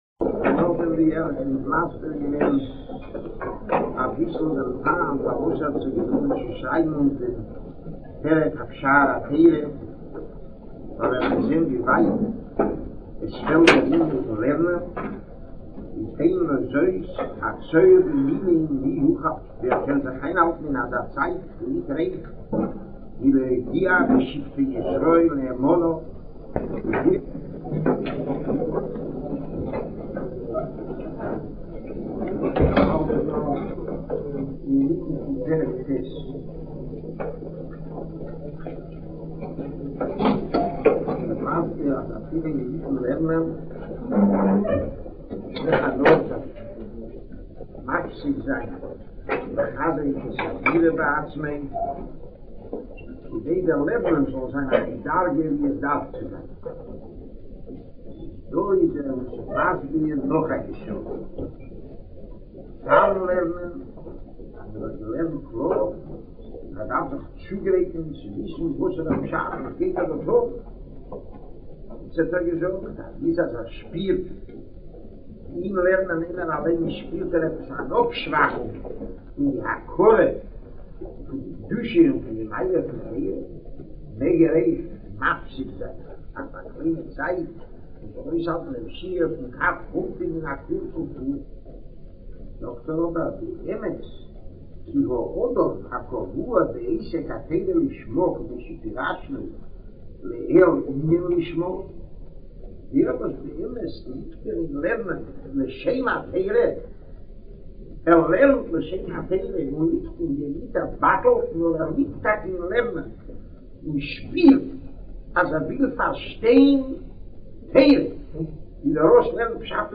giving a shiur on Nefesh Hachaim part II.